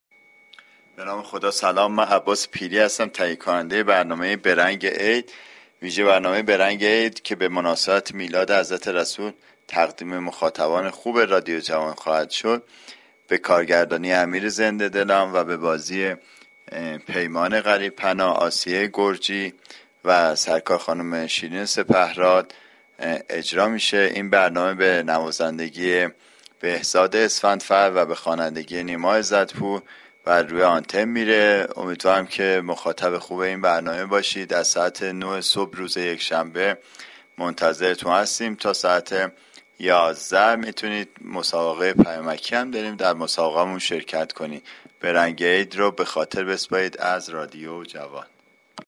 برنامه « به رنگ عید » به چنین مباحثی خواهد پرداخت. این برنامه شامل : گزارش، مسابقه ،آیتم های شاد طنزونمایش است.